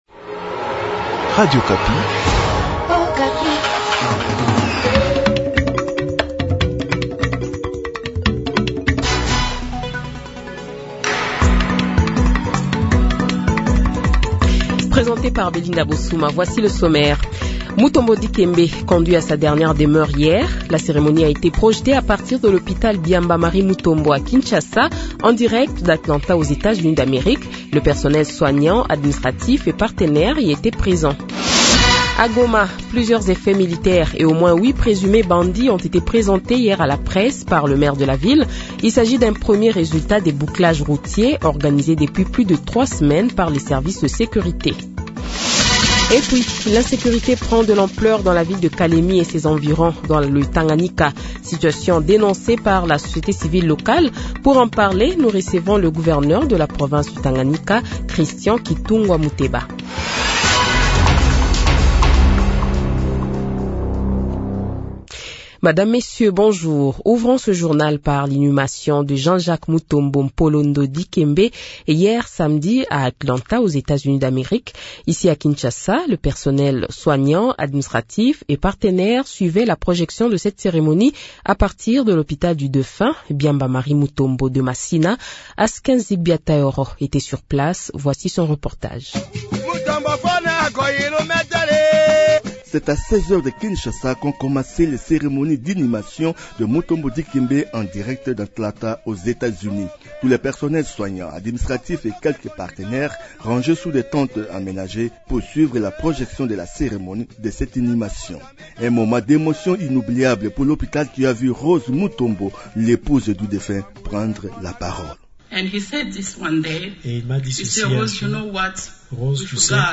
Invité : Christian Kitungwa Muteba, gouverneur de la province du Tanganyika